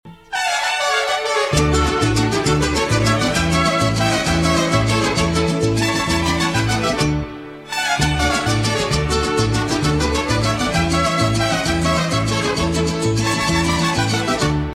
en bonus deux pistes sonores initialement prévue pour les sonneries d'intercours : une musique classique d'un compositeur célèbre du pays et une son plus "inattendu".